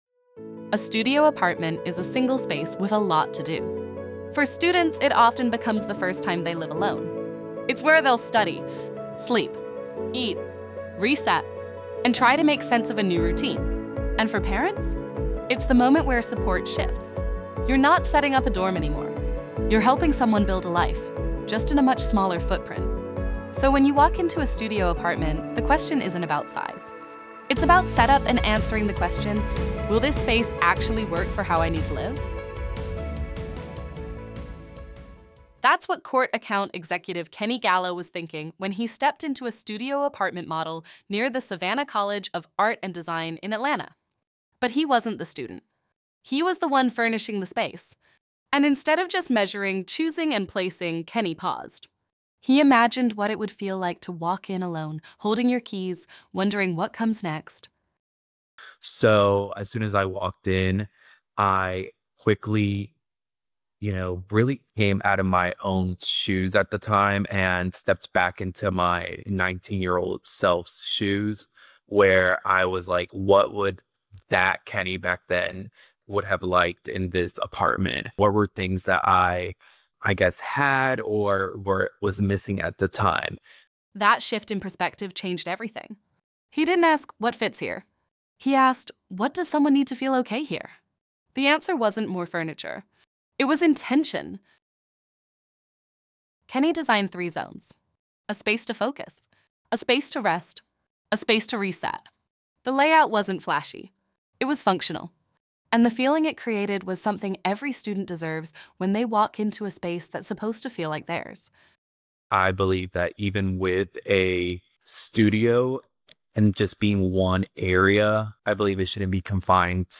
The above podcast episode was generated using AI based on an interview transcript. While the content remains true to the original conversation, the voices, tone, and delivery were synthesized and do not represent actual recordings of the speakers.